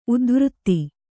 ♪ udvřtti